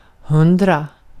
Uttal
Synonymer ett hundra Uttal Okänd accent: IPA: /ˈhɵndra/ Ordet hittades på dessa språk: svenska Ingen översättning hittades i den valda målspråket.